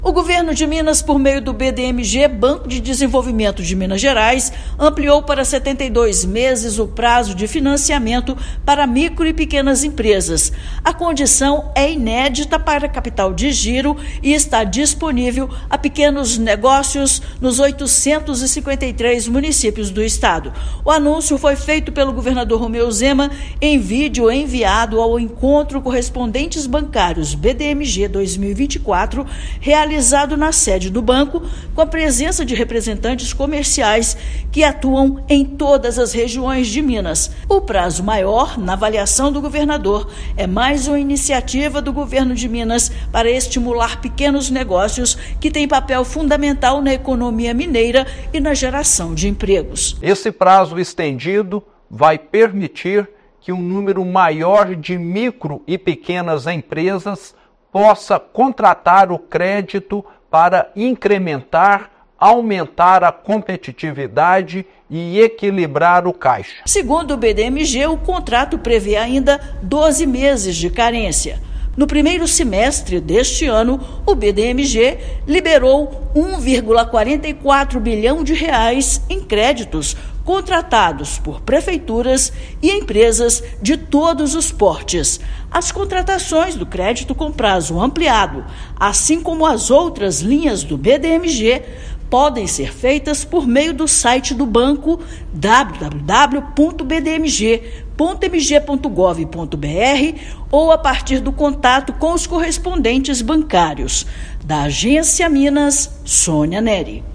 Anúncio foi feito pelo governador Romeu Zema em encontro com correspondentes bancários. Ouça matéria de rádio.